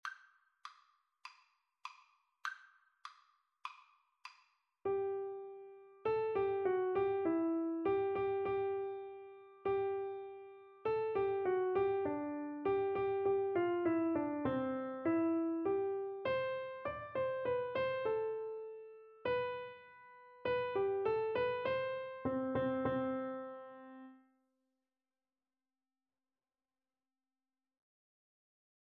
4/4 (View more 4/4 Music)
Piano Duet  (View more Beginners Piano Duet Music)